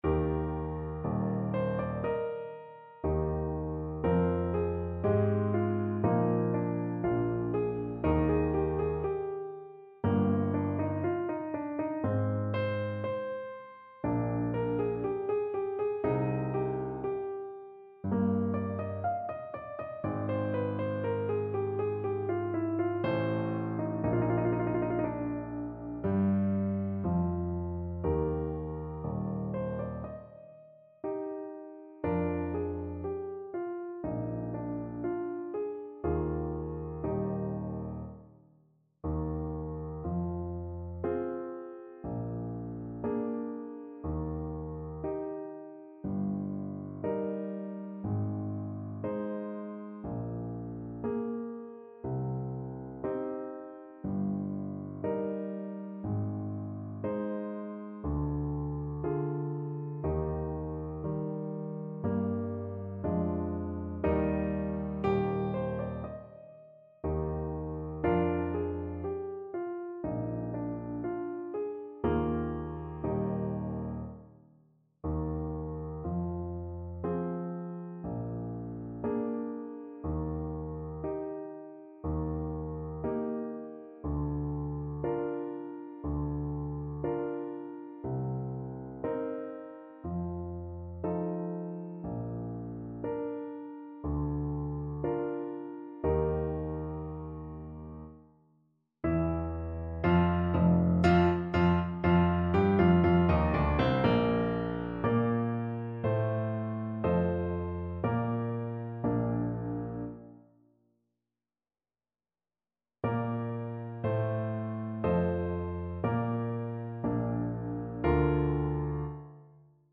Adagio
Classical (View more Classical Saxophone Music)